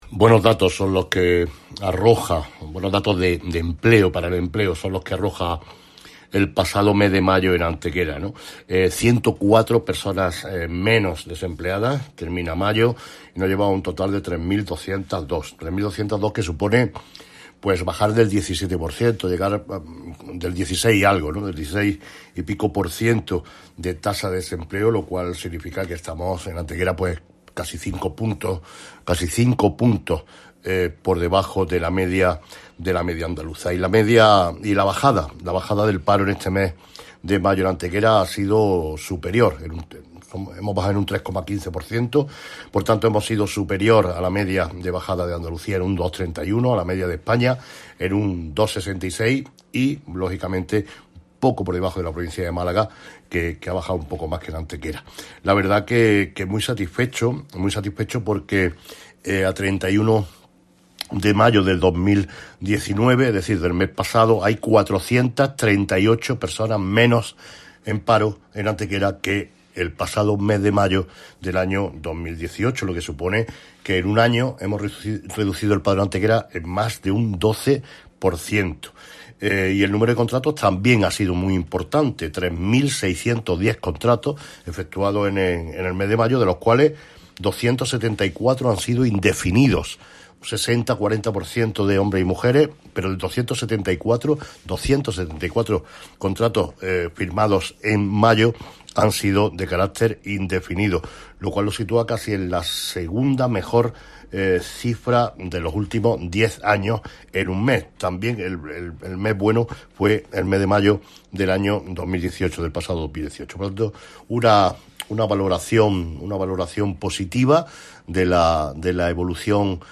Generar Pdf martes 4 de junio de 2019 Concluyó el mes de mayo con una bajada en el paro de 104 personas descendiendo hasta los 3.202 desempleados en Antequera Generar Pdf El alcalde de Antequera, Manolo Barón, informa sobre los datos relativos al desempleo en Antequera durante el mes de mayo.
Cortes de voz M. Barón 1386.16 kb Formato: mp3